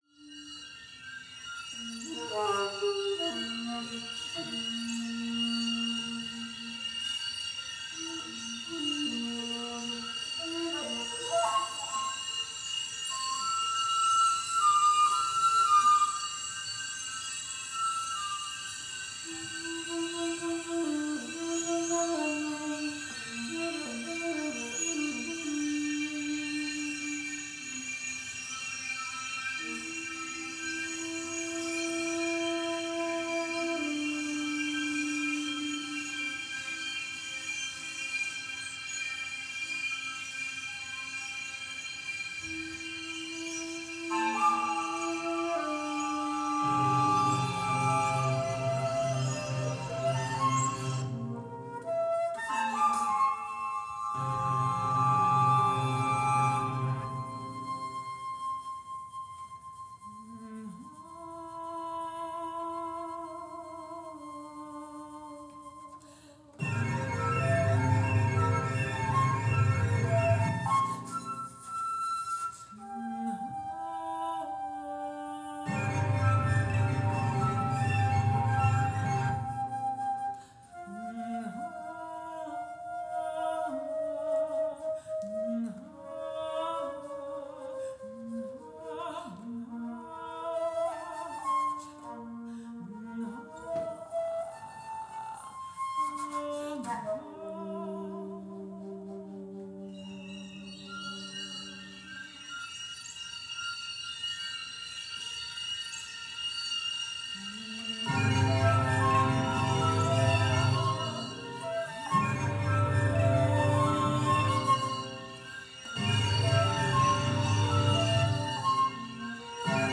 flautist
mezzo-soprano
keyboardist
This piece was my first serious foray into live electronics and multitracking
I really like how the distortion effects filled the space in the New Haven Off-Broadway Theater, where it was performed and recorded